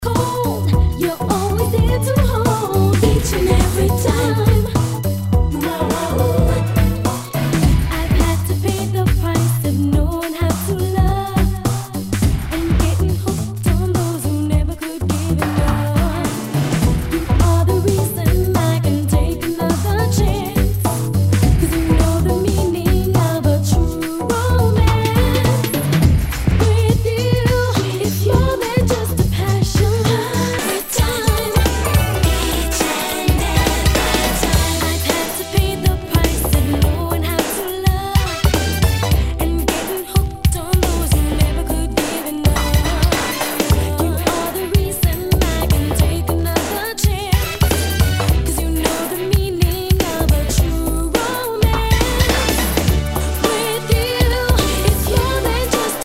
SOUL/FUNK/DISCO
ナイス！フリースタイル / シンセ・ポップ！
全体にチリノイズが入ります。